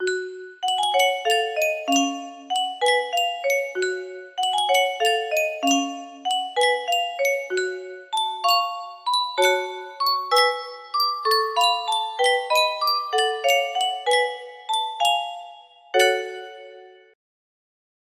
Yunsheng Music Box - Funeral March of a Marionette Y073 music box melody
Full range 60